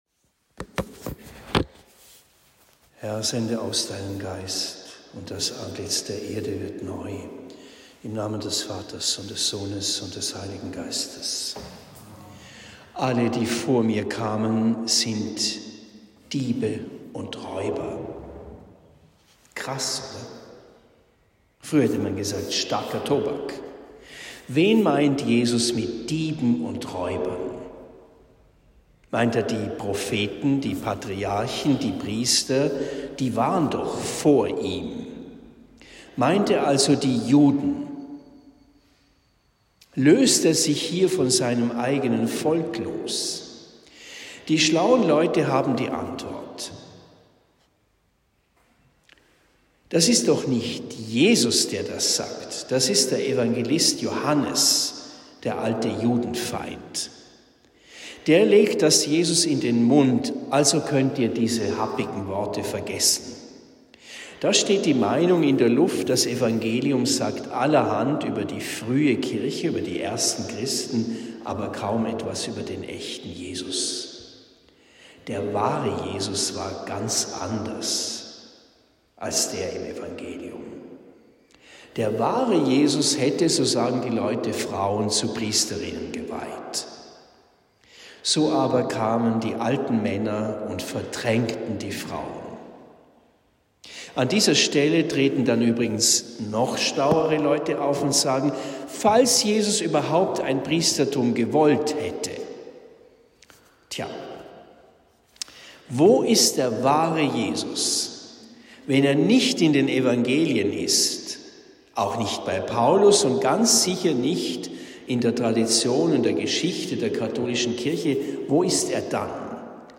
Vierter Sonntag der Osterzeit (Apg 2,14a.36-41; 1 Petr 2,20b-25; Joh 10,1-10) Predigt in Rothenfels am 29. April 2023